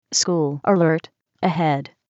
What will happen, as you are driving toward the point, at 4300 ft. away, the red alert border will appear indicating 'Alert: School Zone' and a voice will say, "School Alert Ahead"